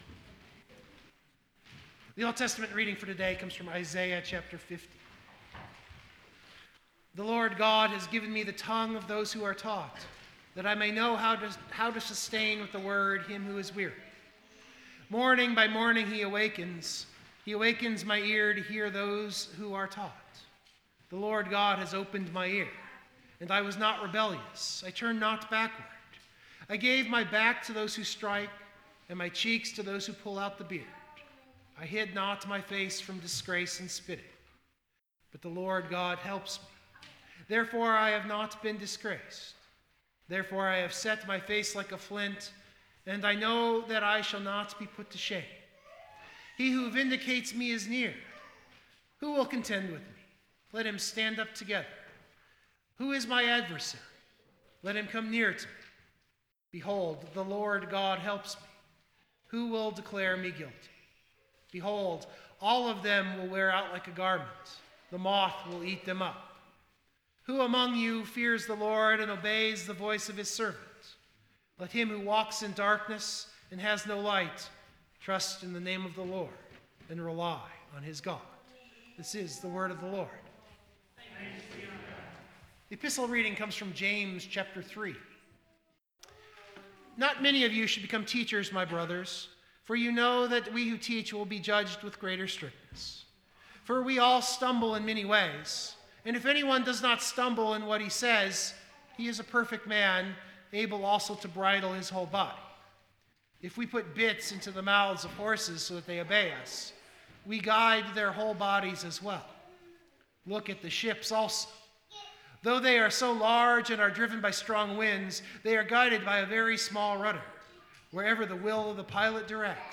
It is also why the sermon is a spoken form.